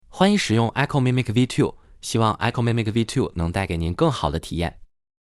echomimicv2_man.wav